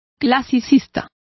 Complete with pronunciation of the translation of classicists.